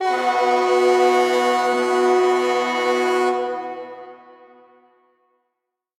goal_horn.ogg